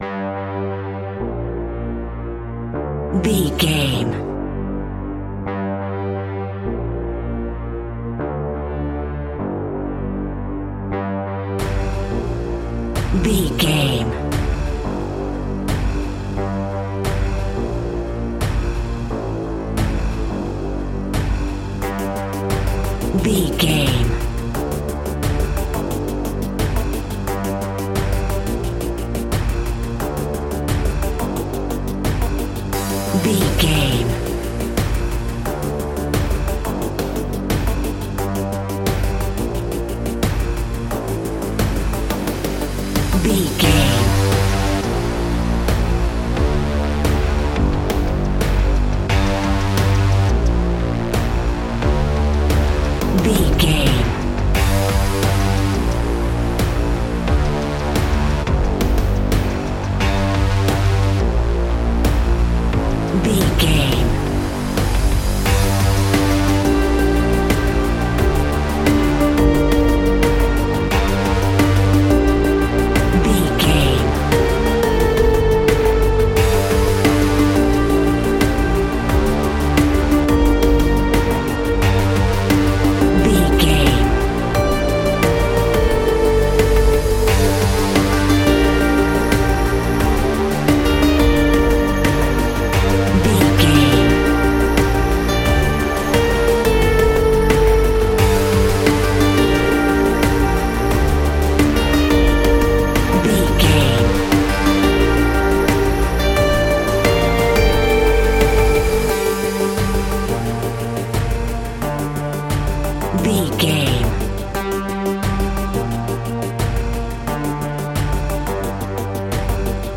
In-crescendo
Thriller
Aeolian/Minor
scary
tension
ominous
dark
haunting
eerie
synthesizer
drum machine
ticking
electronic music
Horror Synths